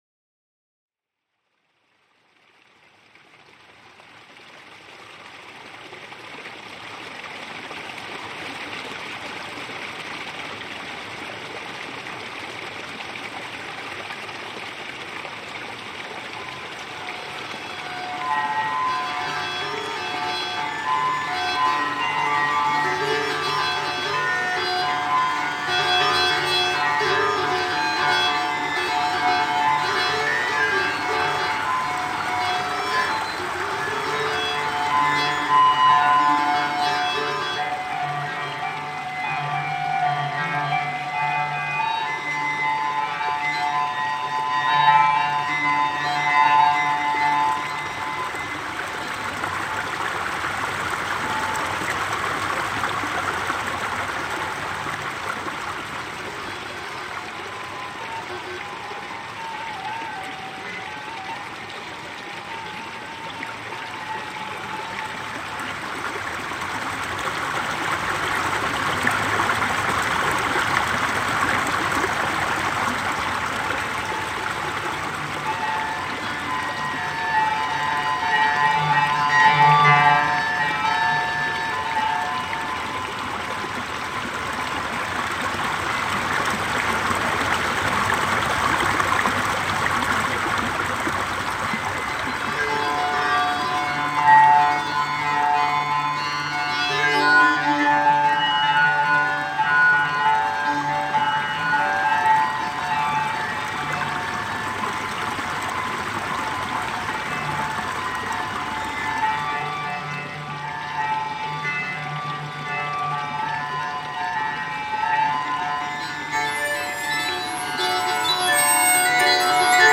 For a while now, I have been strongly pulled towards investigating the possibilities of using feedback from natural sources combined with digital sound-tools in order to trigger and generate collaborative bio-melodies.
Using a fair bit of persistent coaxing and with a combination of various VCV rack modules, selective spectral filtering and some randomly tuned digital resonators reacting to the rich frequencies of the recorded flowing Italian stream sounds - much to my delighted surprise, I discovered that the stream seemed to want to sing these strange meandering contrapuntal melodies to me...No, I was not expecting bagpipes either.
Stream in Vicenza reimagined